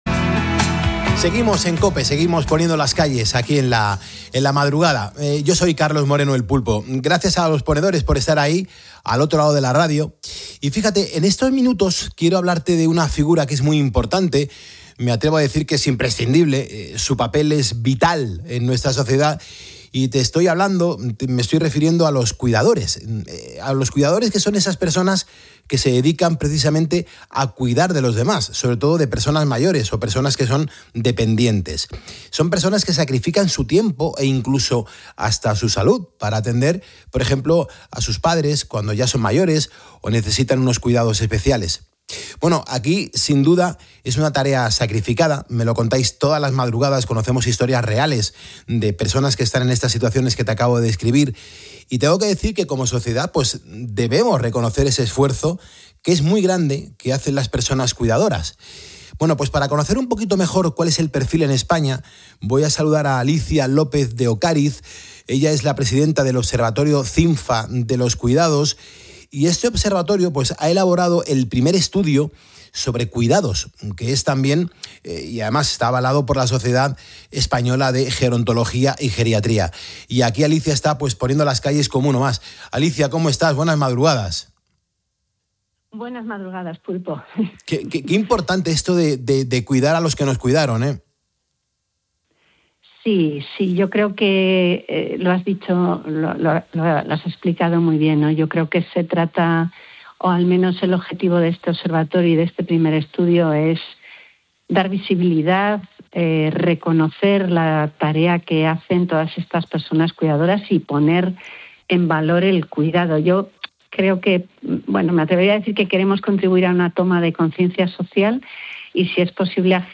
En una entrevista en el programa ' Poniendo las Calles ' de COPE